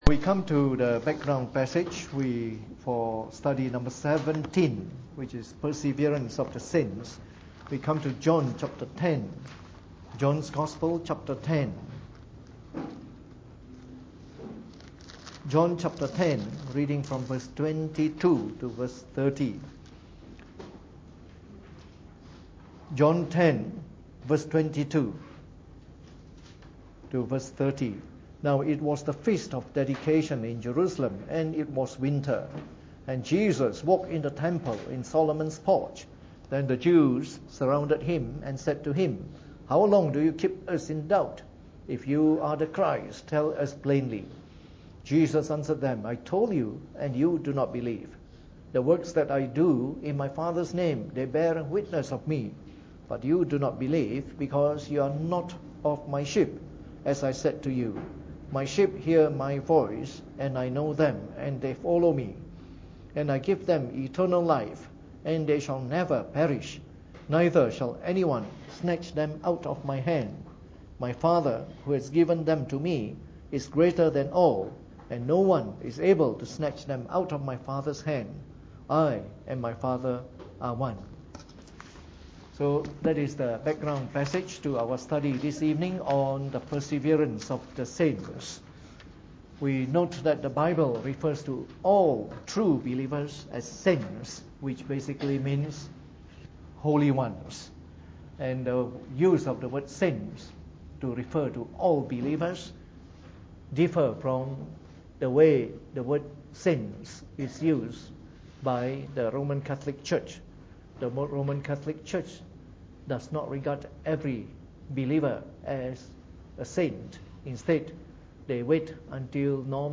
Preached on the 25th of May 2016 during the Bible Study, from our series on the Fundamentals of the Faith (following the 1689 Confession of Faith).